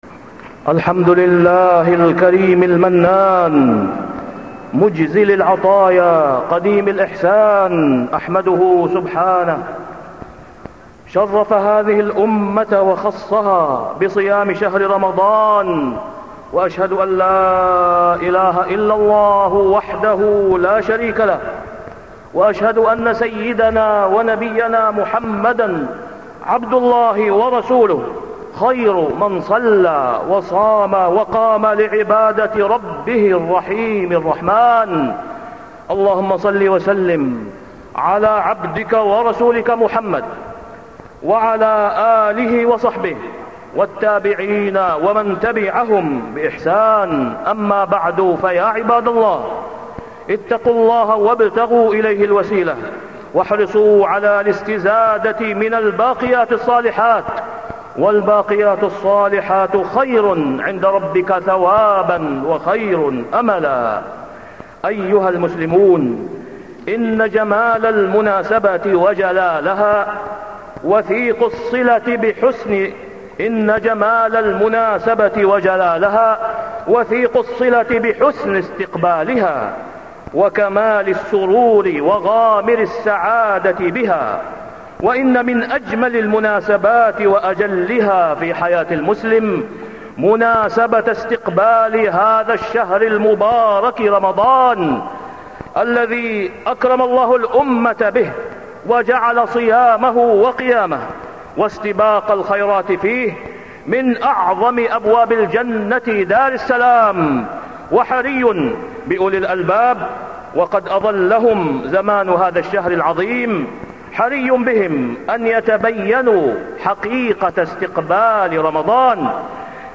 تاريخ النشر ٢٤ شعبان ١٤٢٥ هـ المكان: المسجد الحرام الشيخ: فضيلة الشيخ د. أسامة بن عبدالله خياط فضيلة الشيخ د. أسامة بن عبدالله خياط استقبال رمضان